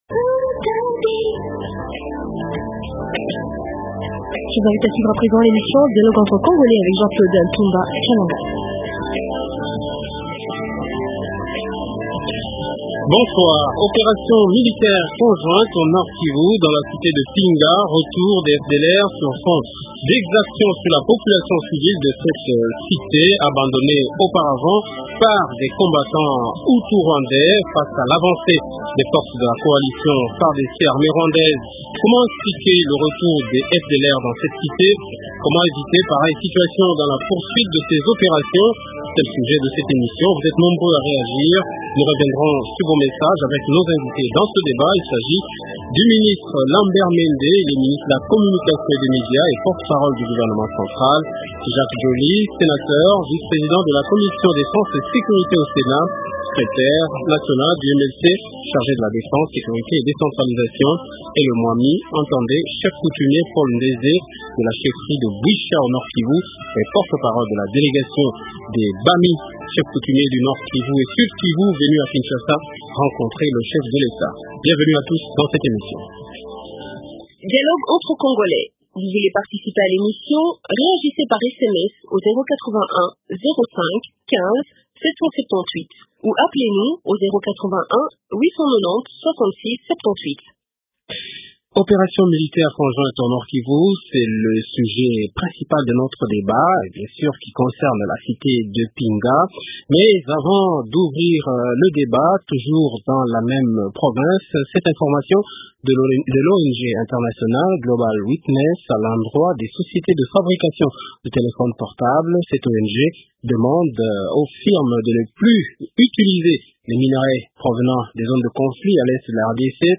Invités Lambert Mende, Ministre de la communication et des médias et porte parole du gouvernement. rnJacques Djoli, Sénateur, vice-président de la commission défense et sécurité au sénat et secrétaire national du Mlc chargé de la défense, sécurité et décentralisation.